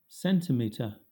Ääntäminen
Southern England British: IPA : /ˈsɛn.tɪ.miː.tə/